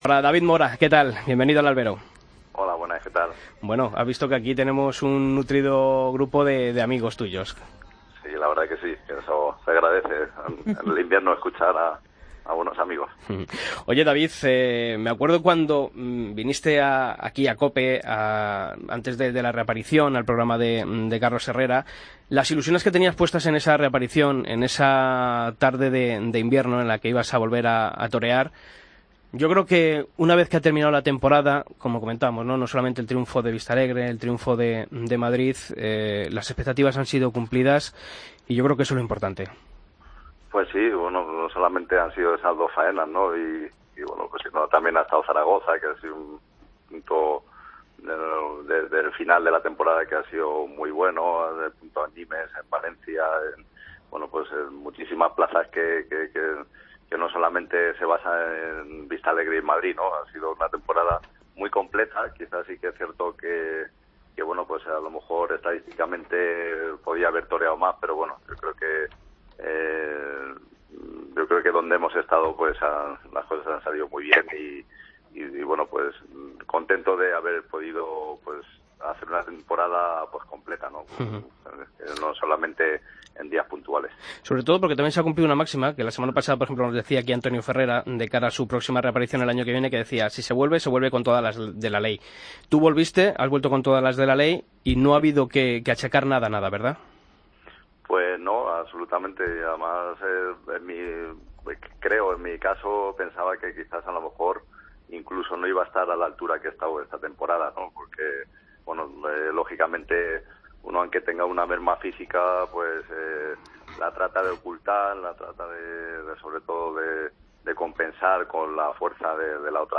Escucha la entrevista a David Mora en El Albero